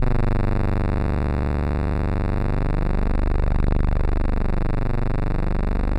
G1_trance_lead_1.wav